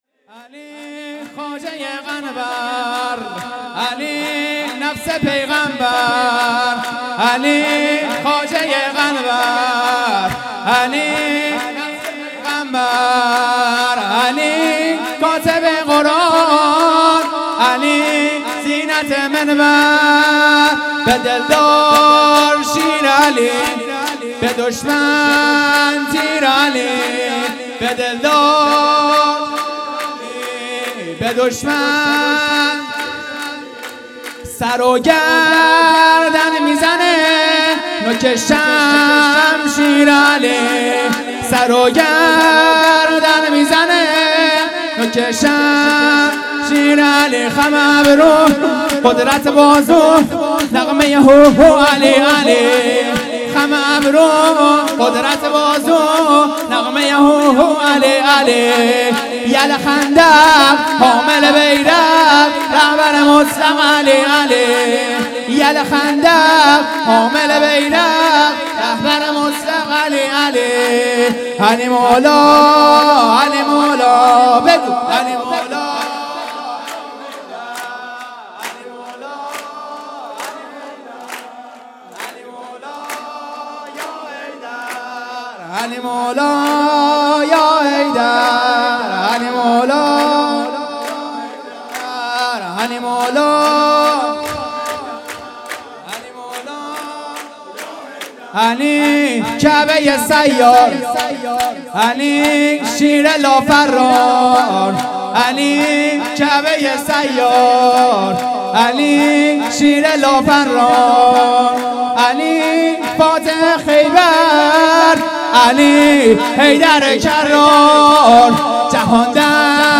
جشن ولادت امام جواد علیه السلام